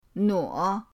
nuo3.mp3